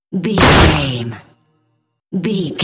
Dramatic hit wood bloody
Sound Effects
heavy
intense
dark
aggressive